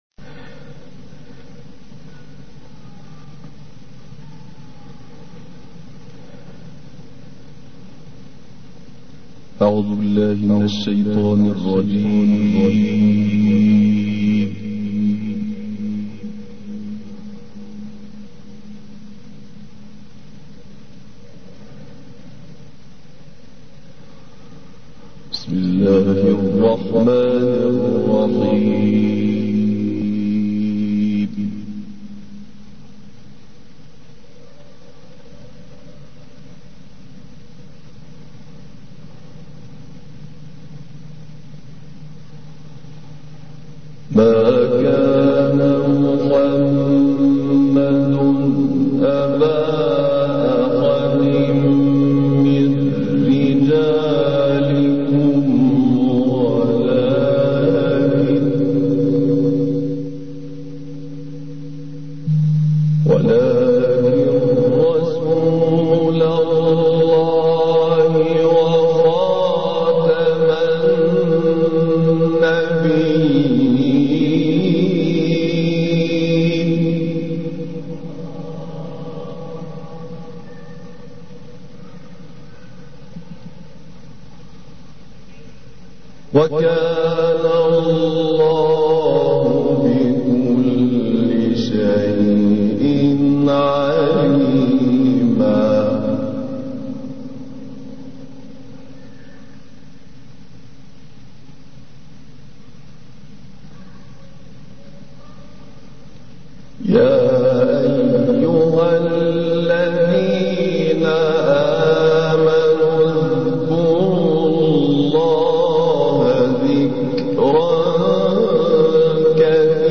تلاوت مجلسی قرآن کریم